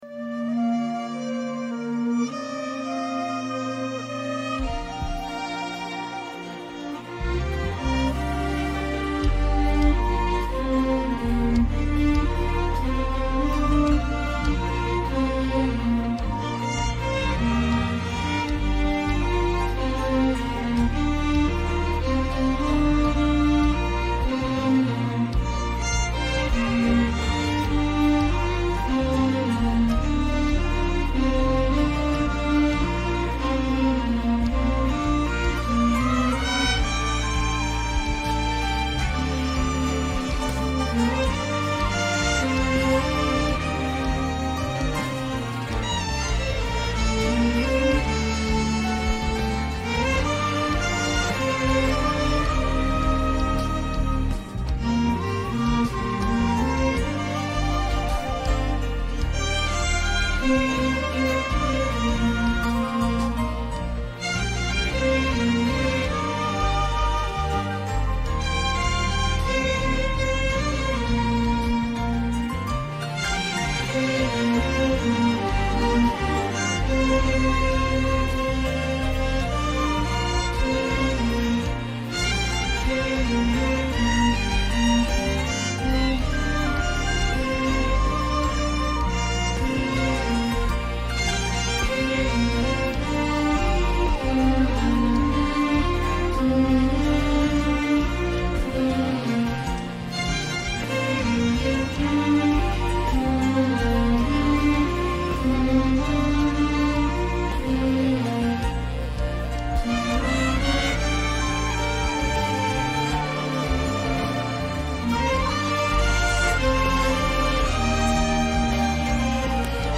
DOWNLOAD THIS INSTRUMENTAL MP3